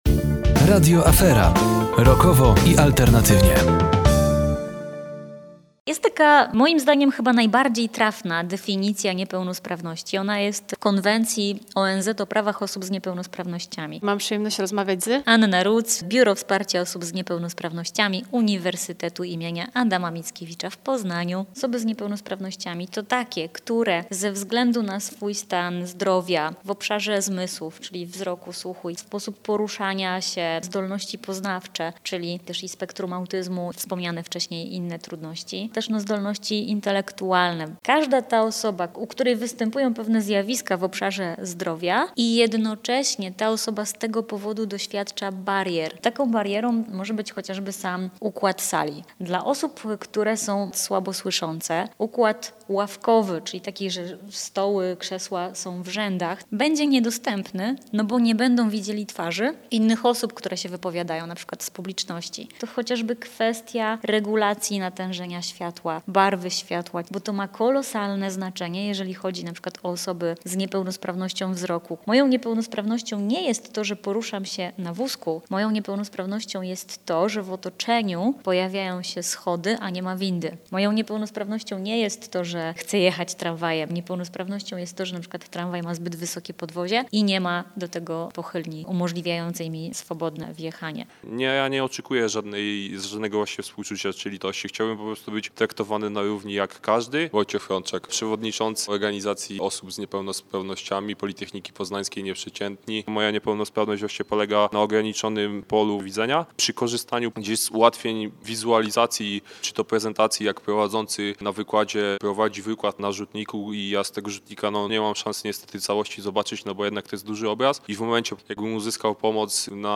w relacji z Konferencji w Radio Afera